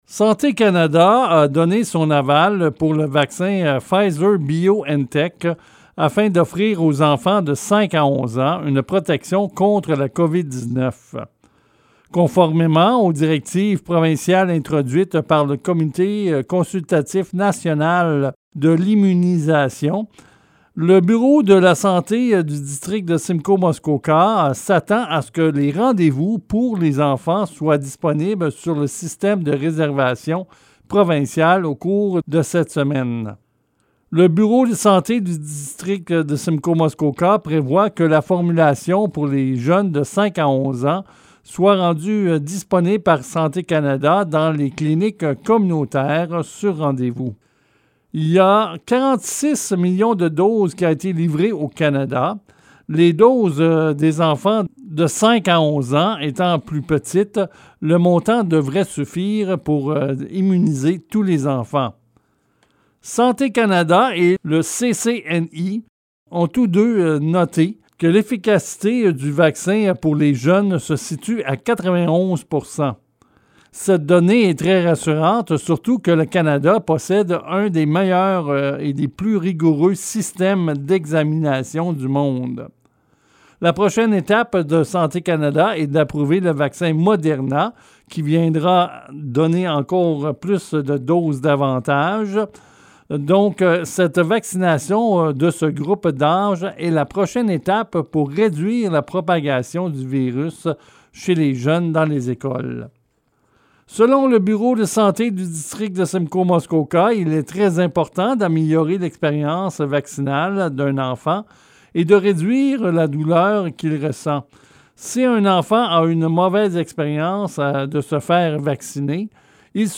Reportage sur la vaccination des 5 à 11 ans